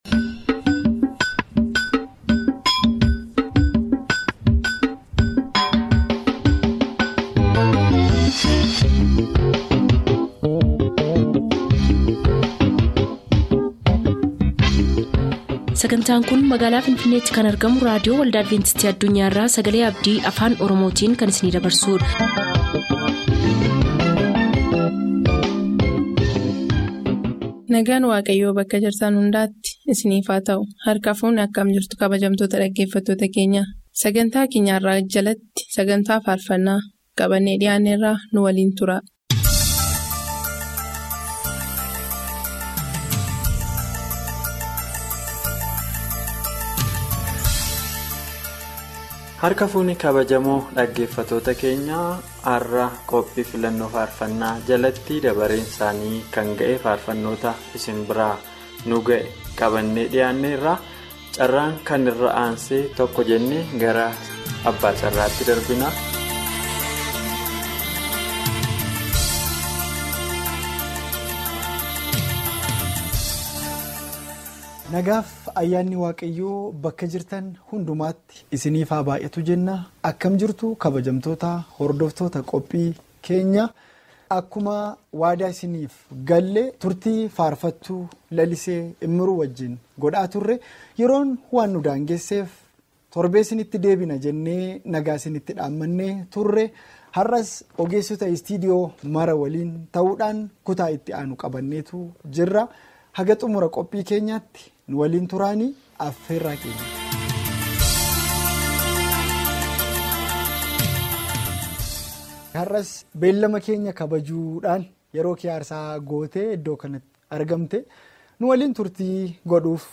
SONG PROGRAME FROM ADVENTIST WORLD RADIO OROMO